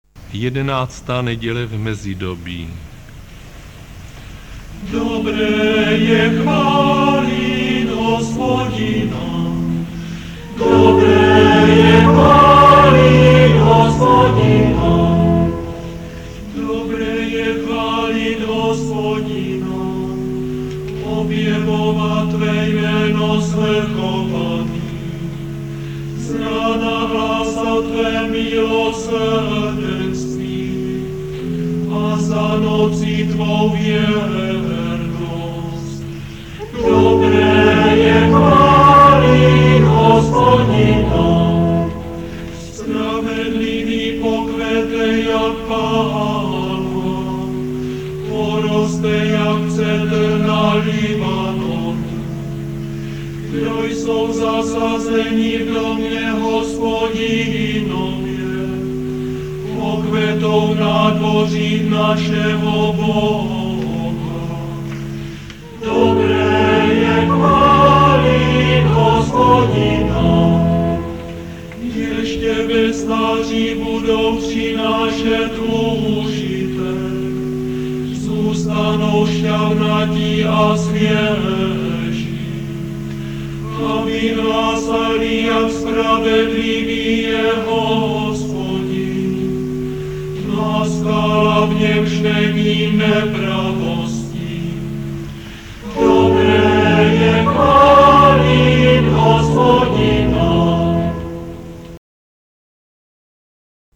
křesťanská hudba, liturgická hudba